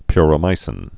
(pyrə-mīsĭn)